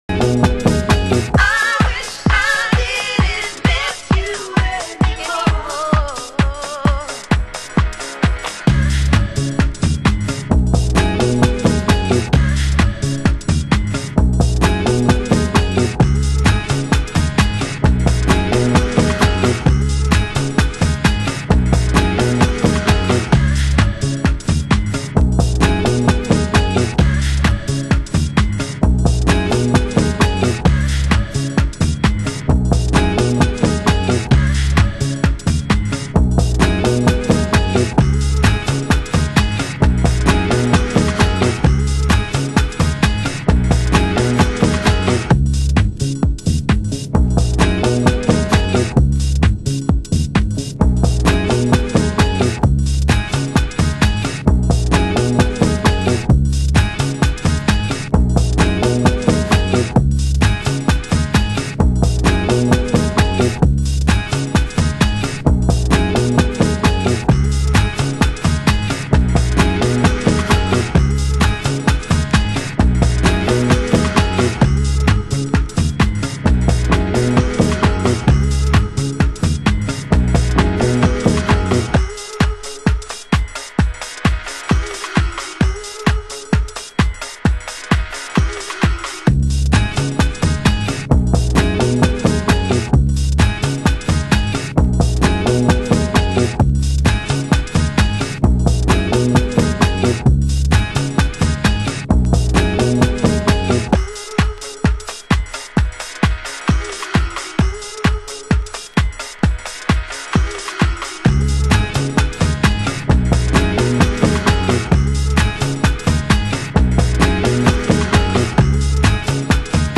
盤質：A1に盤面汚れ 有/チリパチノイズ有/ラベルに少しシミ汚れ有